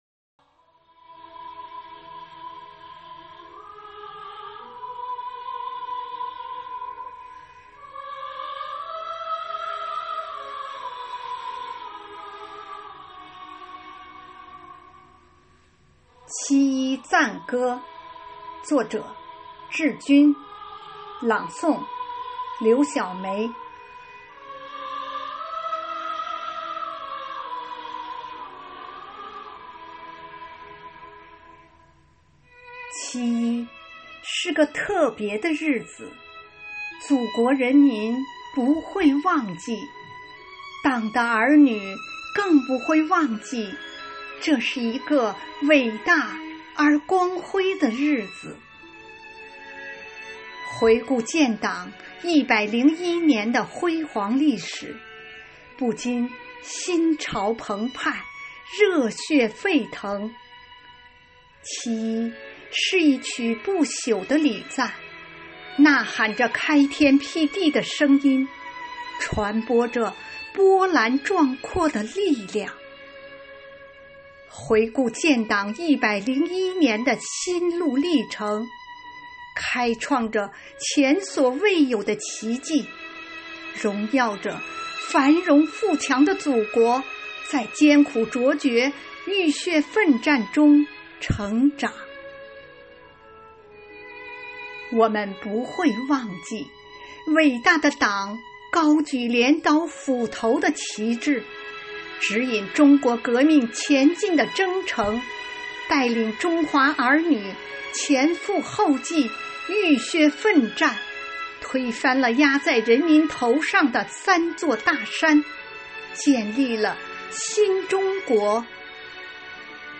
“党在我心中，喜迎二十大”主题朗诵会